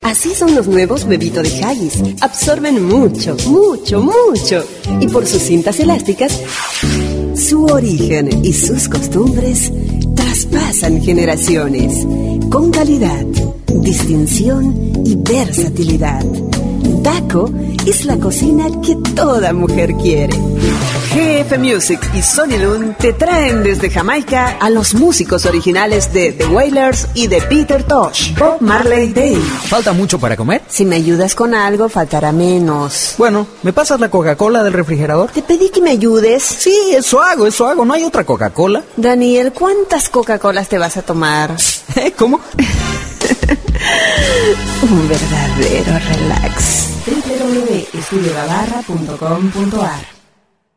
Explora la versatilidad vocal de nuestros locutores, expertos en locución dulce, enérgica, institucional, narrativa, promocional y sensual.
Locutora Latinoamericana de Doblaje. ACTUACIÓN: Sí MANEJO DE IDIOMAS: Castellano Neutro 100% , Acento latino. OBSERVACIONES: Voz versátil para todo tipo de grabaciones. DEMO GENERAL: